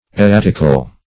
Search Result for " erratical" : The Collaborative International Dictionary of English v.0.48: Erratical \Er*rat"ic*al\, a. Erratic.